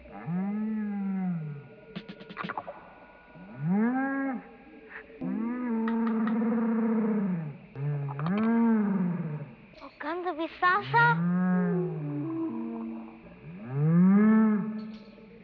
Alice makes some strange sounds while she is looking in puzzlement at the magic rocks. She sounds like a cross between a whale and a Wookiee!
whale or Wookiee?
Wookie_or_Whale.wav